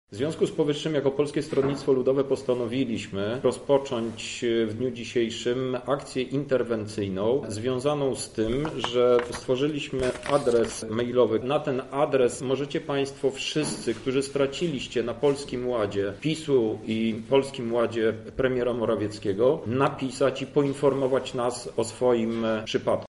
-mówi europoseł Krzysztof Hetman, wiceprezes PSLu i szef partii w województwie lubelskim.